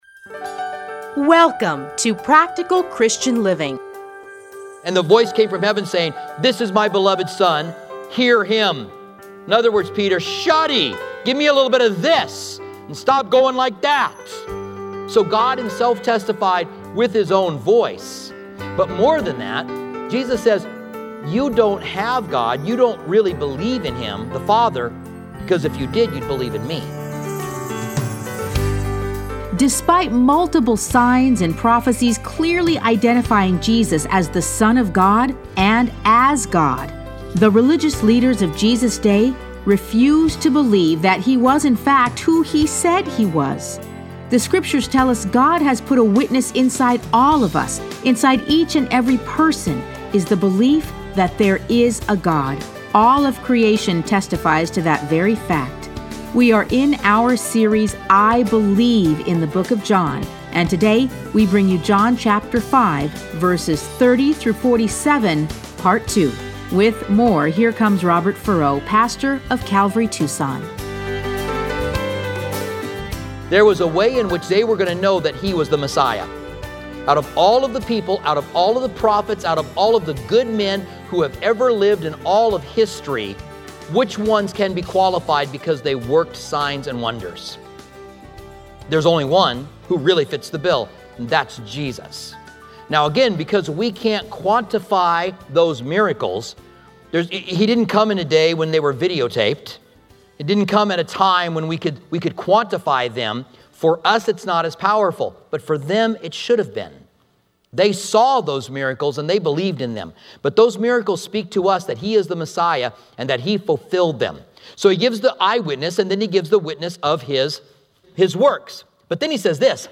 Listen to a teaching from John 5:30-47.